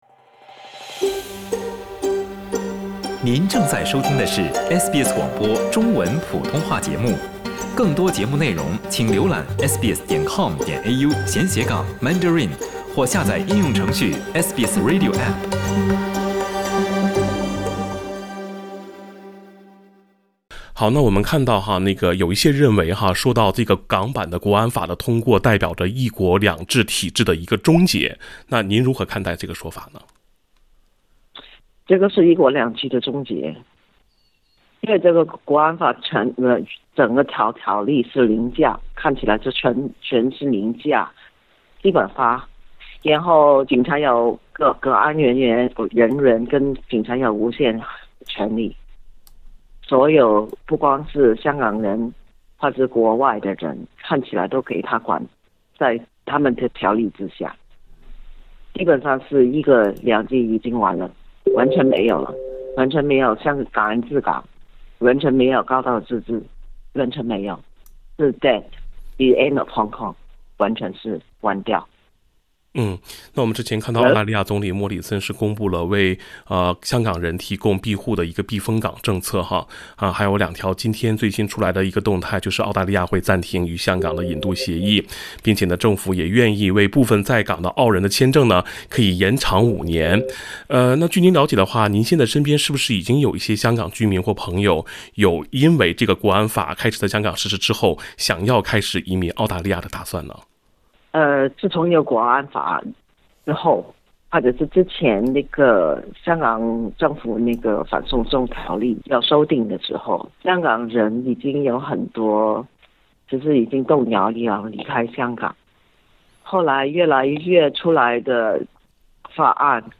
(点击封面图片，收听采访录音） READ MORE 【分析】居澳港人5年签证可能会是怎样的签证？